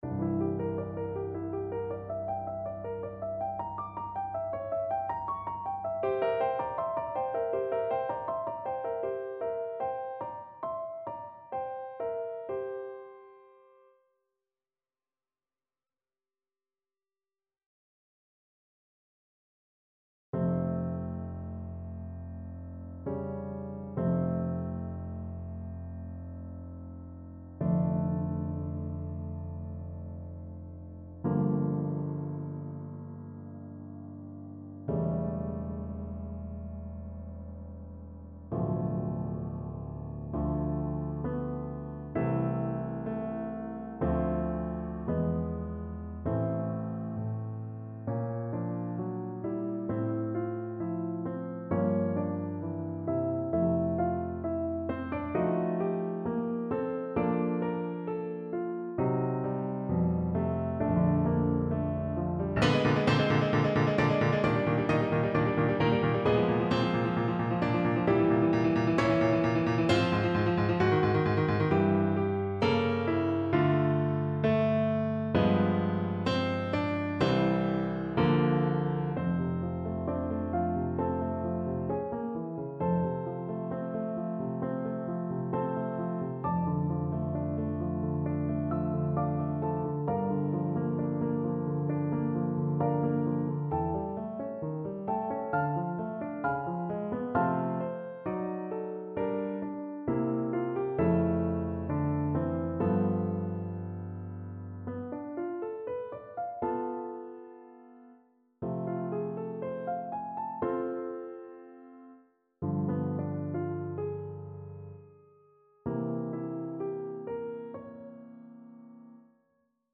Free Sheet music for Trombone
Trombone
A major (Sounding Pitch) (View more A major Music for Trombone )
= 80 Lentamente
4/4 (View more 4/4 Music)
Classical (View more Classical Trombone Music)